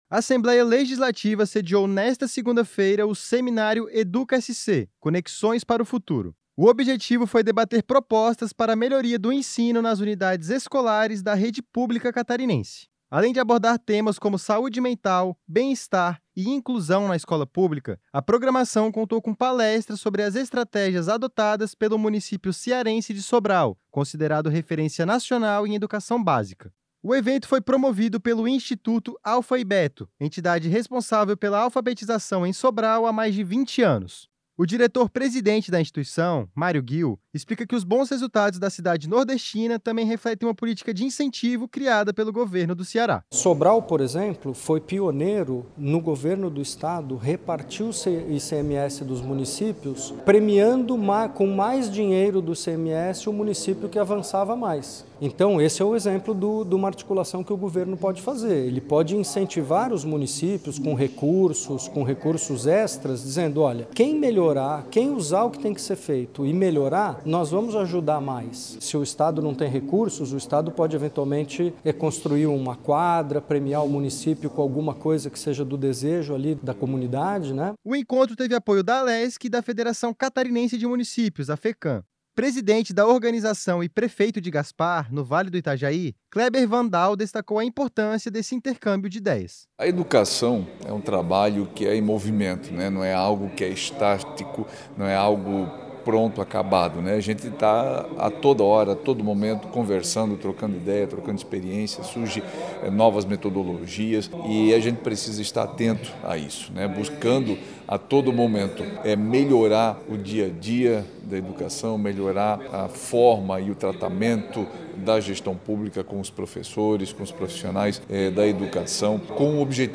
Entrevistas com:
- Kleber Wan-Dall, presidente da Federação Catarinense de Municípios (Fecam) e prefeito do município de Gaspar.